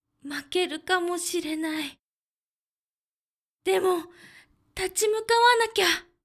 ボイス
パワフル女性
josei_makerukamoshirenai.demotatimukawanakya.mp3